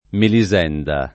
[ meli @$ nda ]